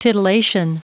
Prononciation du mot titillation en anglais (fichier audio)